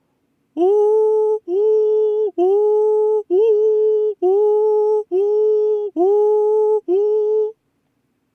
音量注意！
呼気での裏声が上手く出せる場合に限定されますが、呼気で裏声を発声した後、間髪入れずに吸気で発声してみましょう。
ここが意識できていたら、呼気と同じような音高／音色／音量になるはずです。